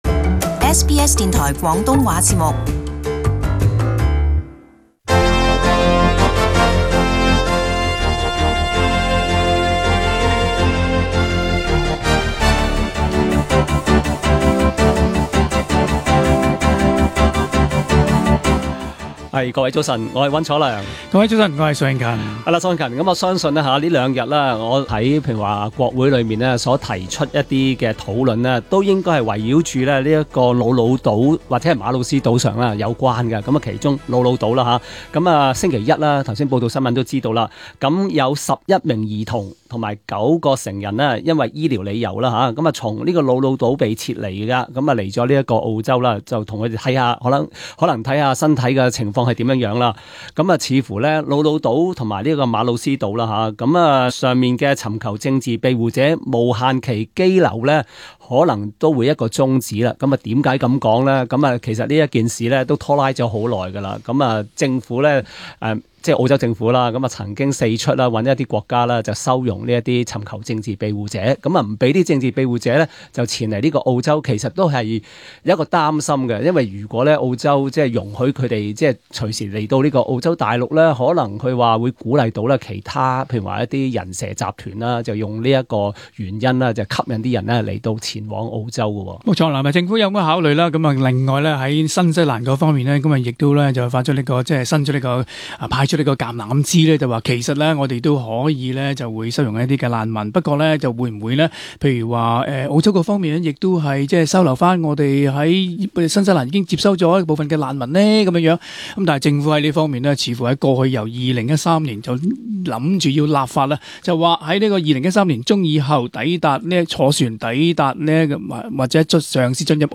【時事報導】議員促莫理遜政府將兒童撤離瑙魯島